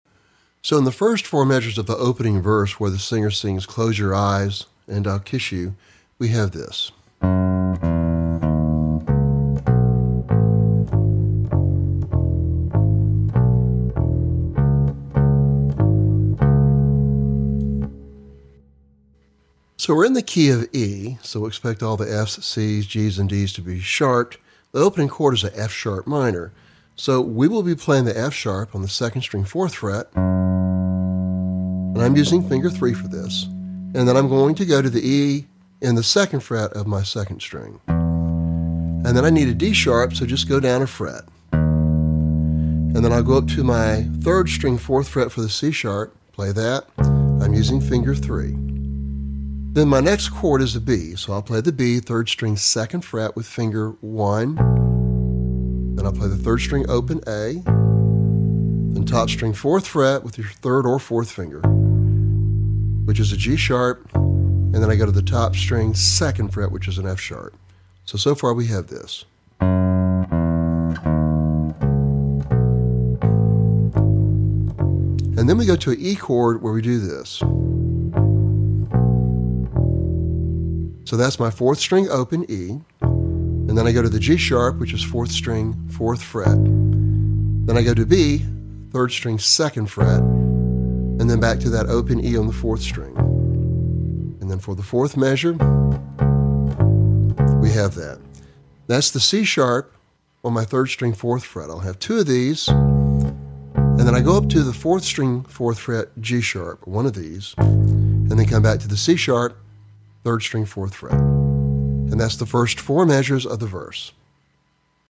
Lesson Sample
For Bass Guitar.